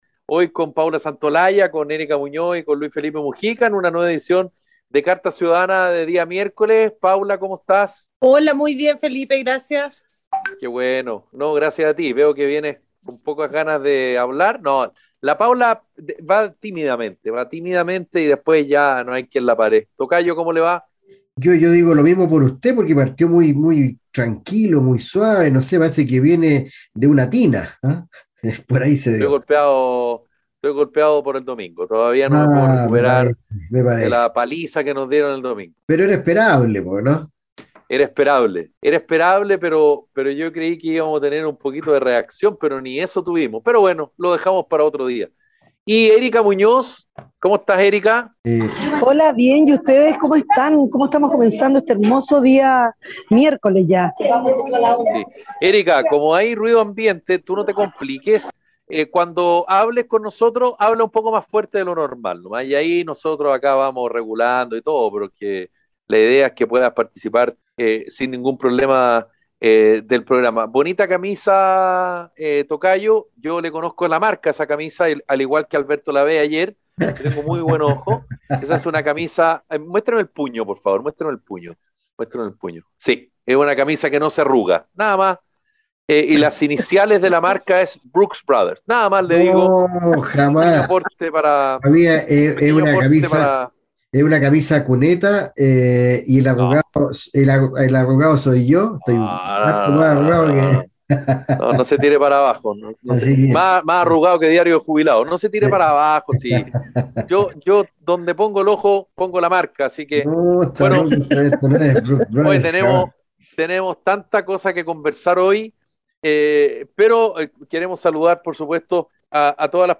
programa de conversación y análisis de la contingencia en Chile.